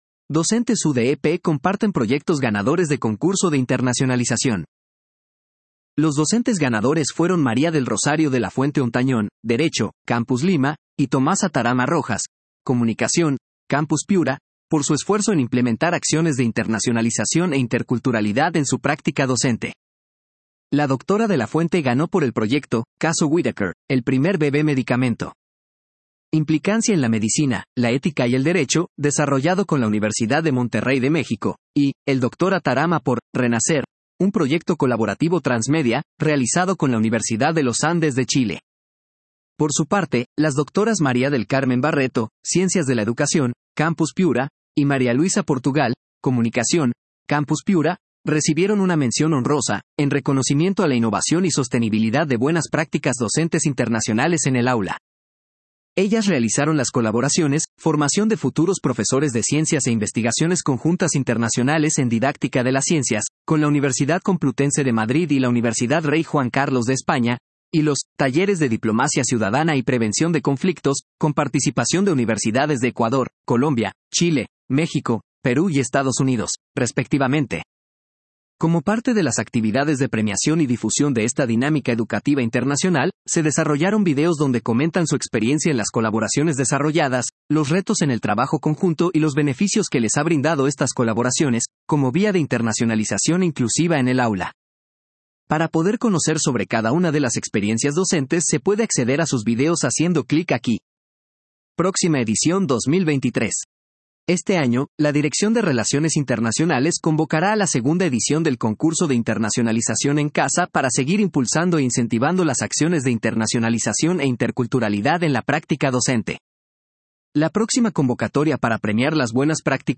Lector implementado por DIRCOM ● Universidad de Piura